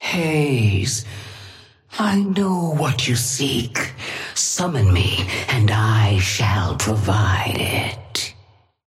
Sapphire Flame voice line - Haze, I know what you seek. Summon me and I shall provide it.
Patron_female_ally_haze_start_02.mp3